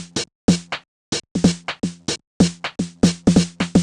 cch_percussion_loop_snip_125.wav